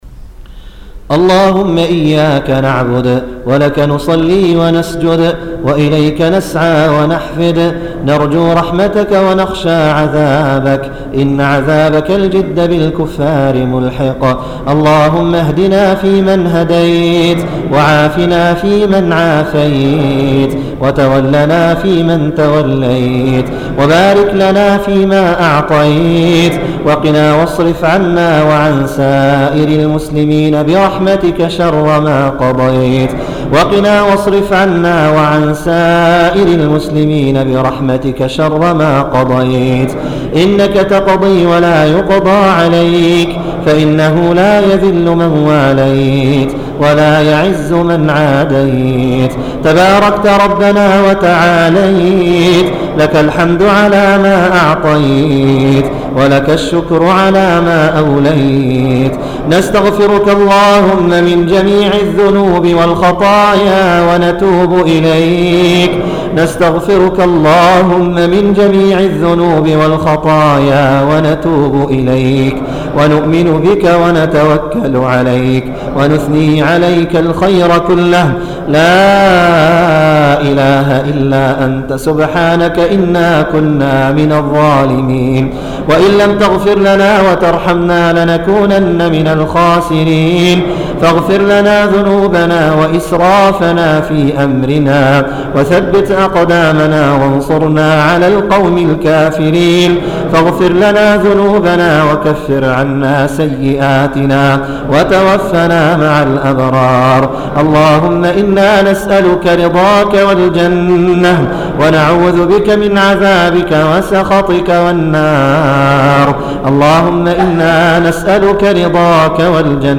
دولة الكويت
دعاء القنوت